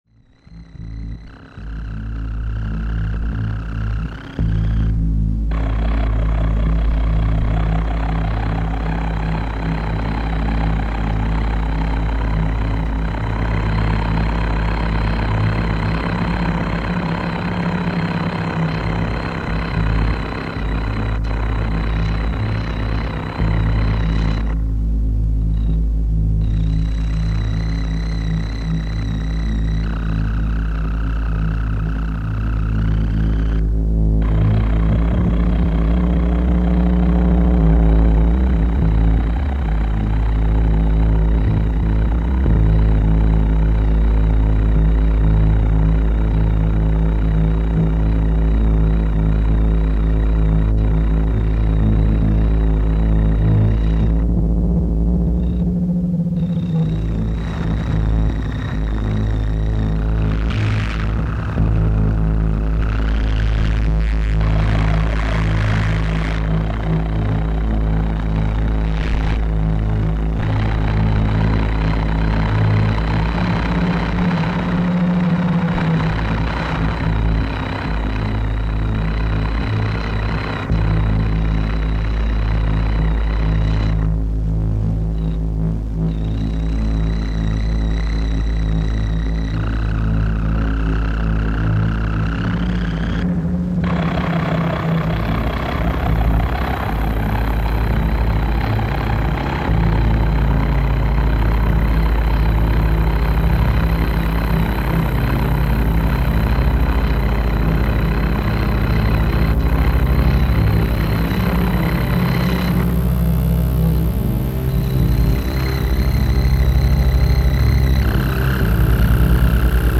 solo noise project
dirt-nap dirge and parasitic crunch